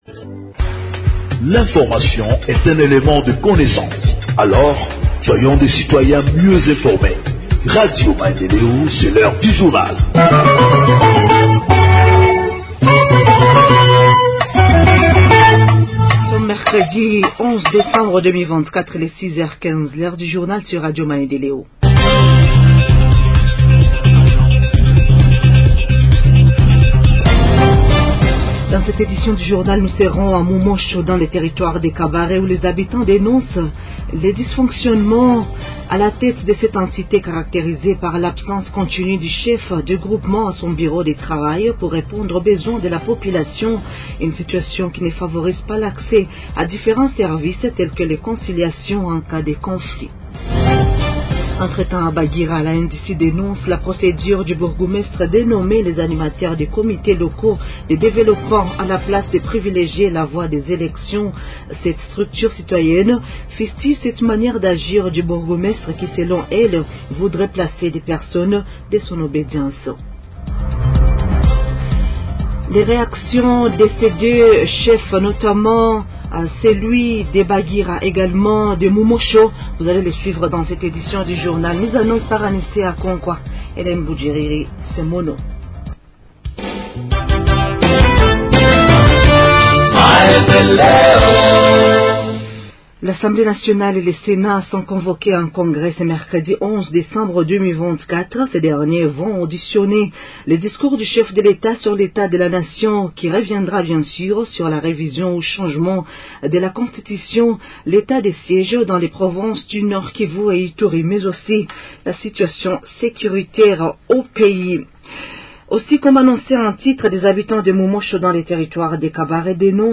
Journal en Français du 11 Décembre 2024 – Radio Maendeleo